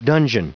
Prononciation du mot dungeon en anglais (fichier audio)
Prononciation du mot : dungeon